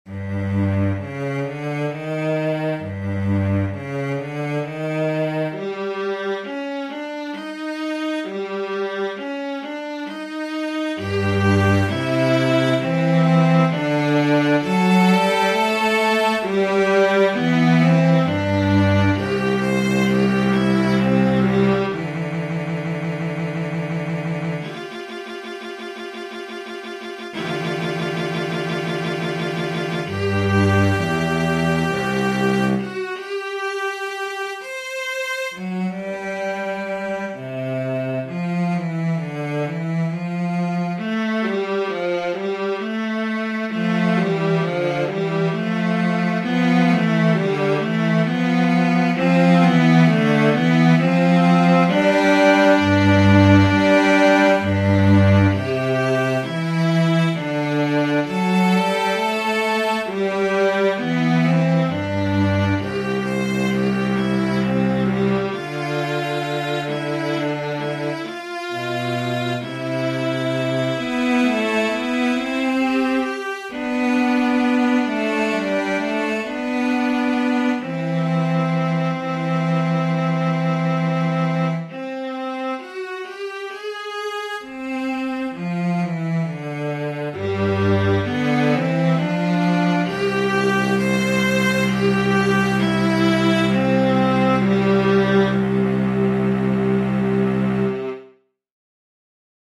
viola
cello
Pure audio version - perfect for quiet reflection